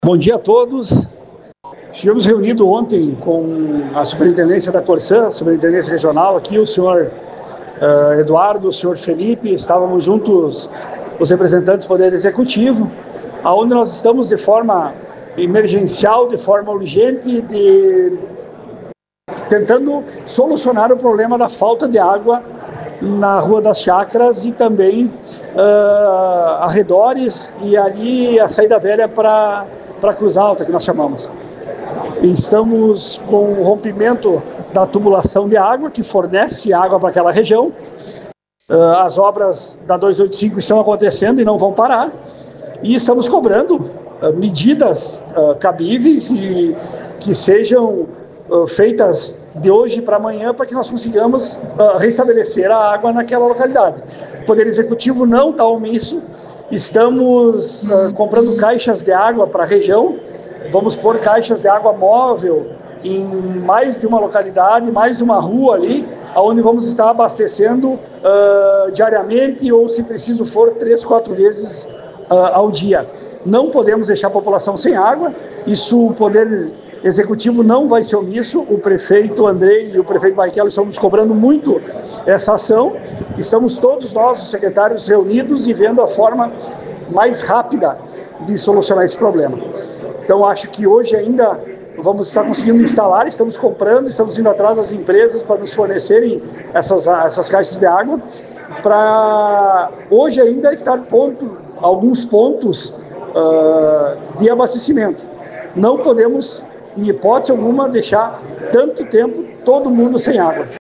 O secretário de Desenvolvimento Urbano, Obras e Trânsito de Ijuí, Fábio Franzen, disse à reportagem da Rádio Progresso que o município está tentando solucionar o problema de forma urgente.